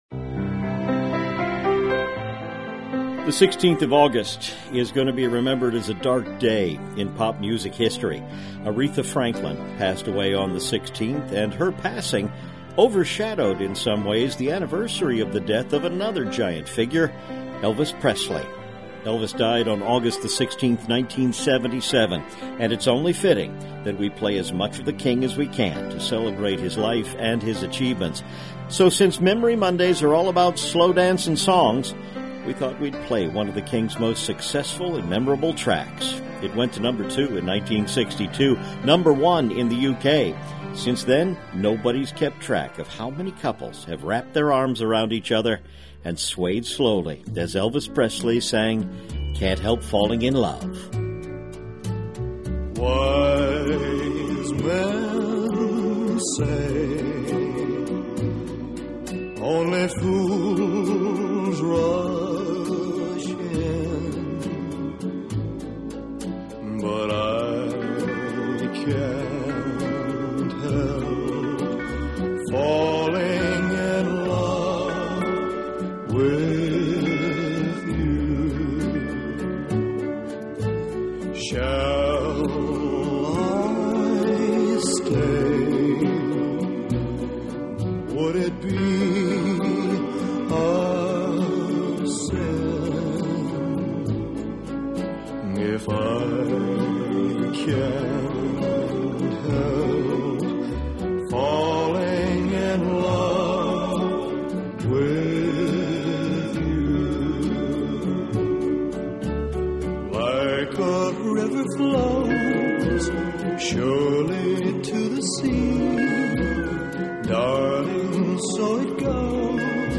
We can help make up for it in today's slow-dancing song.